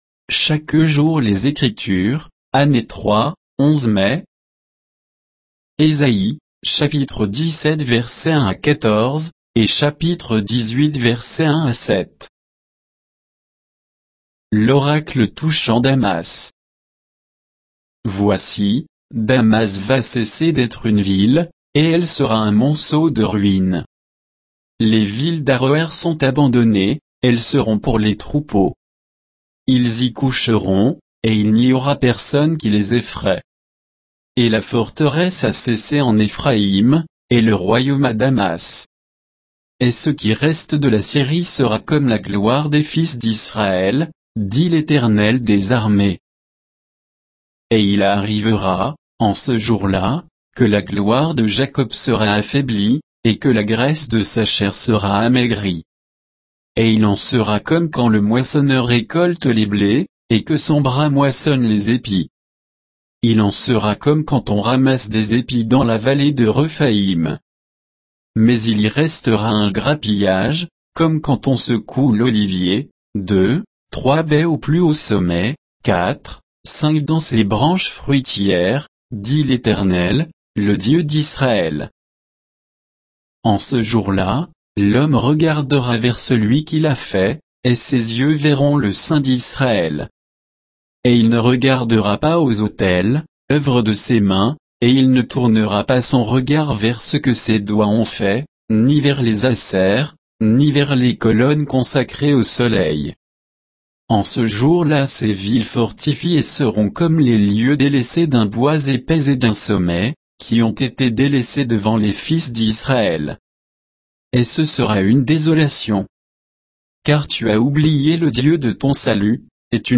Méditation quoditienne de Chaque jour les Écritures sur Ésaïe 17, 1 à 18, 7